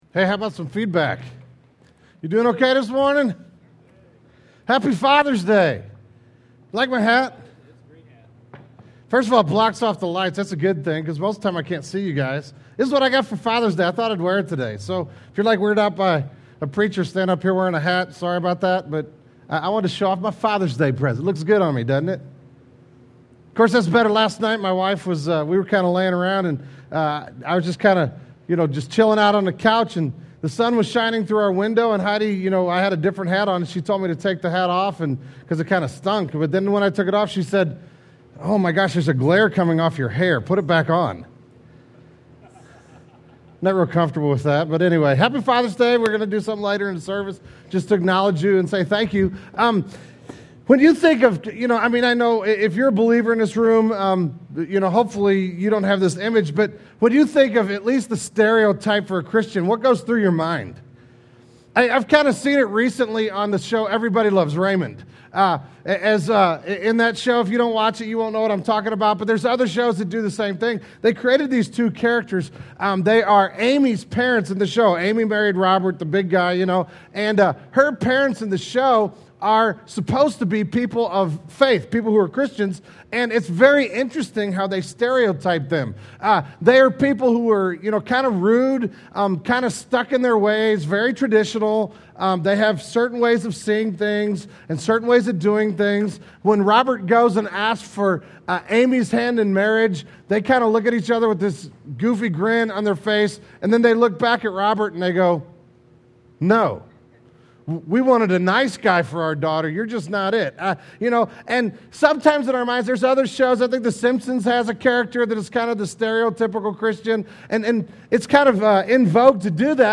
The June 2006 Sermon Audio archive of Genesis Church.